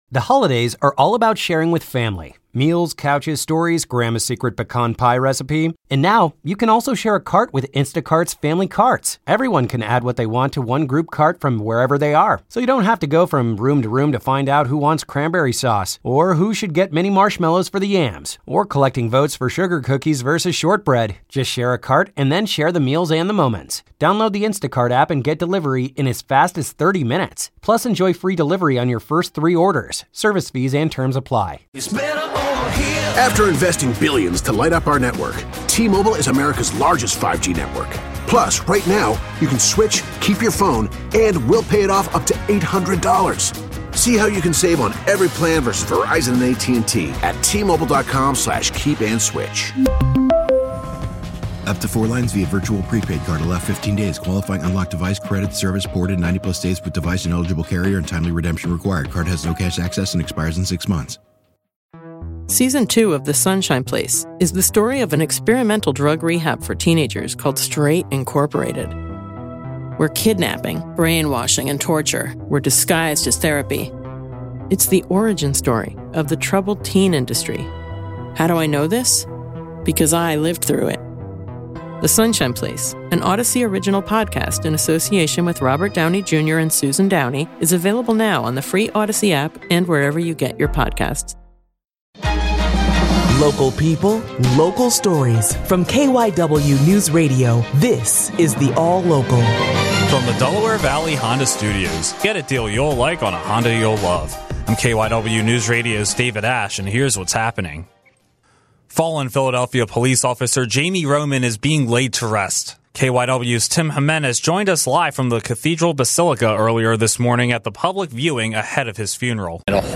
The KYW Newsradio All-Local - Thursday, September 19, 2024 (Midday Edition)